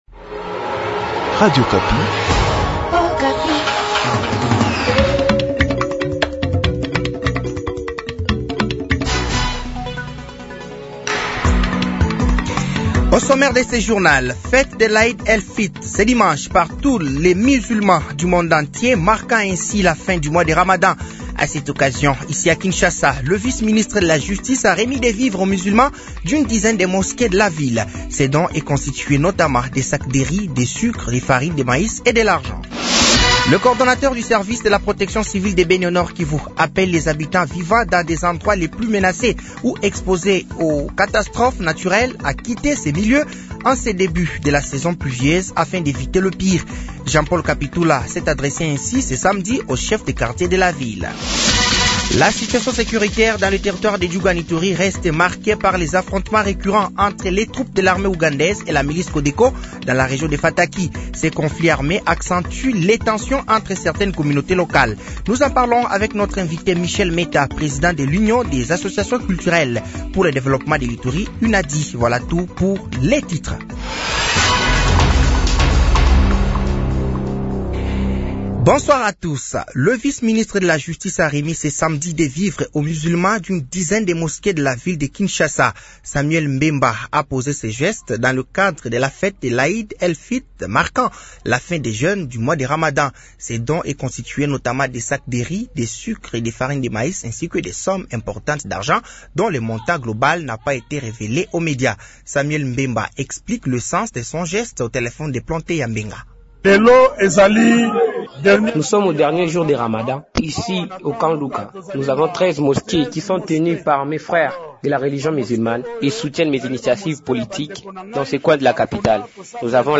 Journal français de 18h de ce dimanche 30 mars 2025